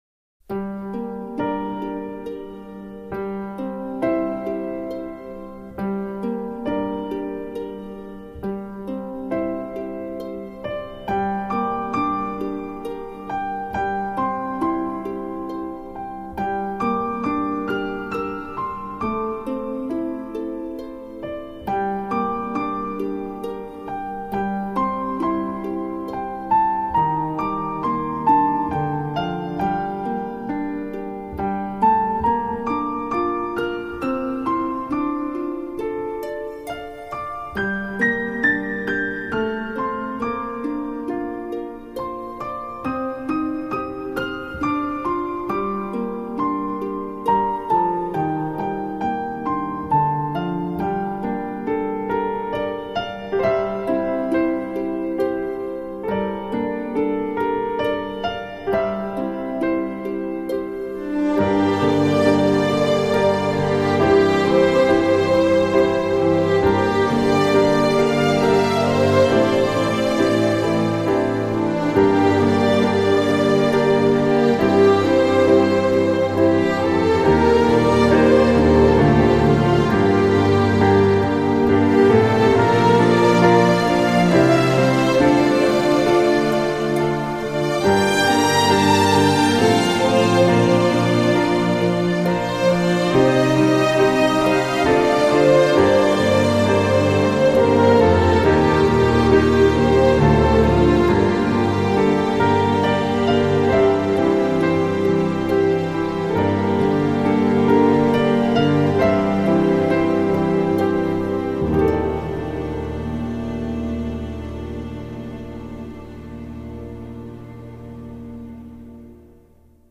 当前位置：首 页 > 清音雅韵 >天籁钢琴 > 查看文章